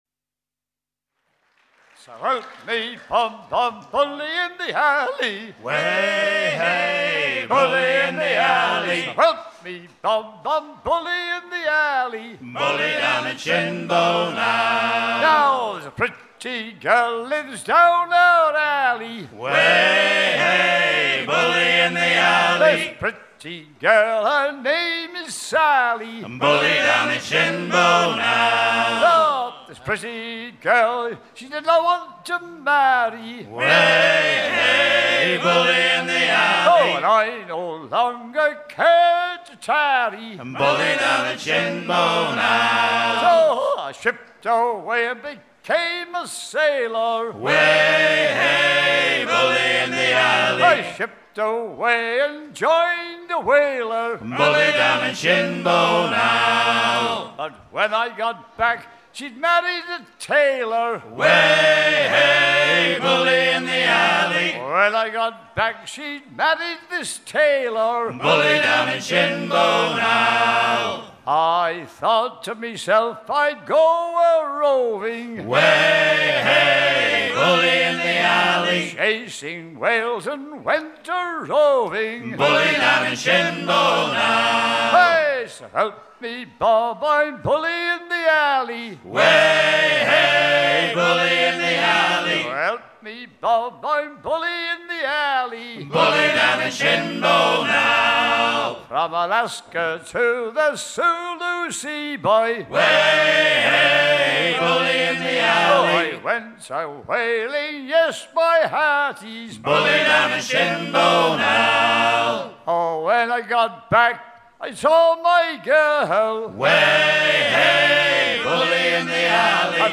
chant d'origine noire
à hisser main sur main
Pièce musicale éditée